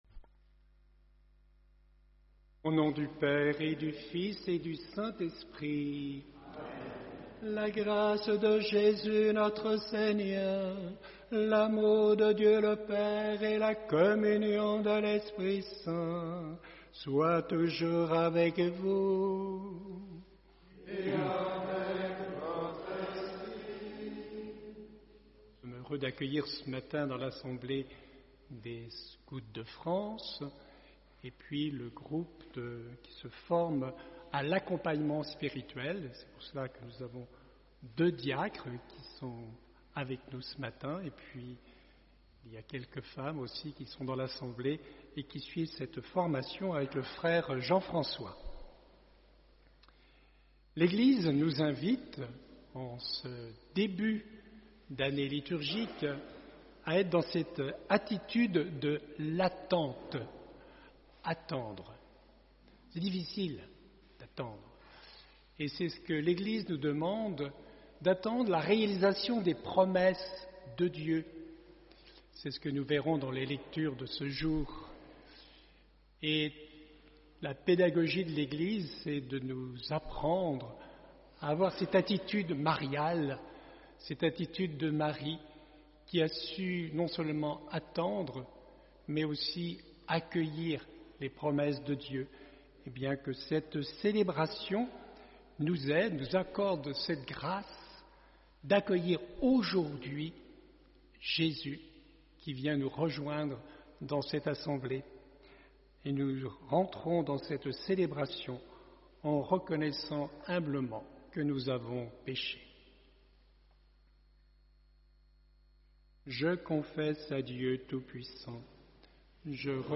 Accueil Nous sommes heureux d’accueillir ce matin, dans l’assemblée, des Scouts de France, et puis le groupe qui se forme […]